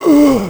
c_zombim5_hit1.wav